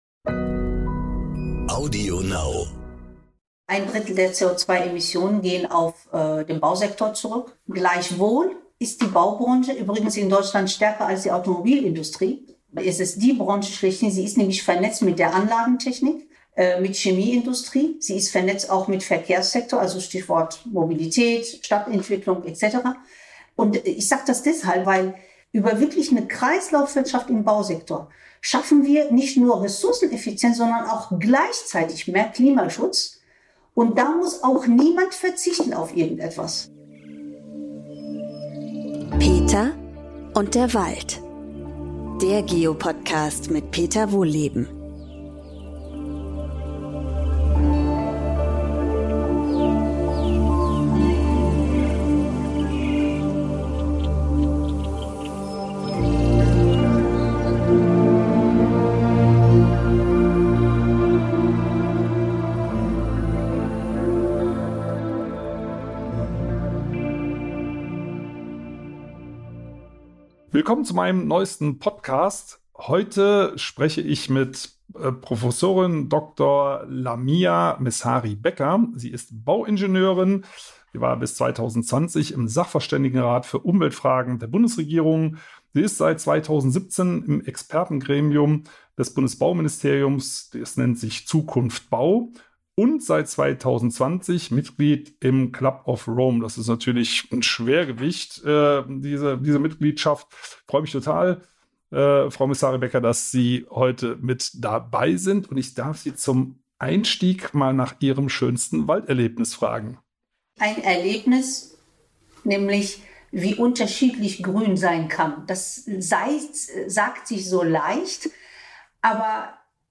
In dieser Folge trifft Peter Wohlleben die Bauingenieurin, Professorin und Mitglied des Club of Rome Lamia Messari-Becker, die für eine ganzheitliche Nachhaltigkeitsbetrachtung im Bauwesen eintritt.